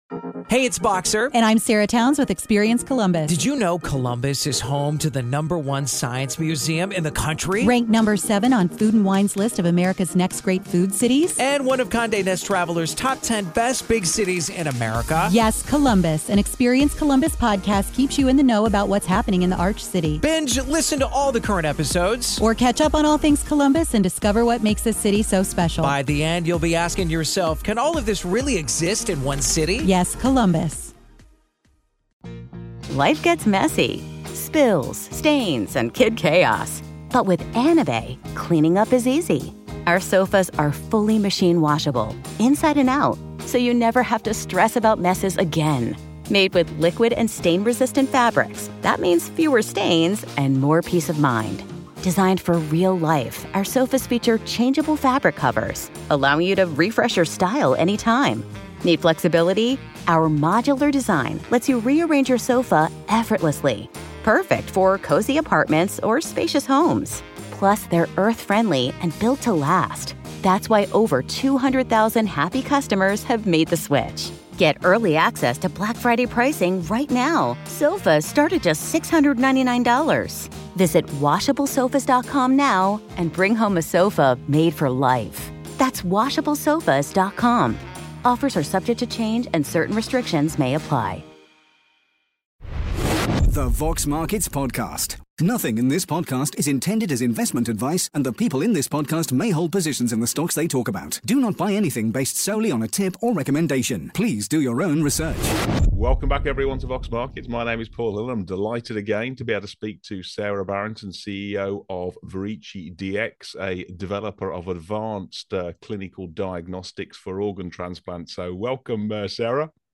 The Vox Markets Podcast / Q&A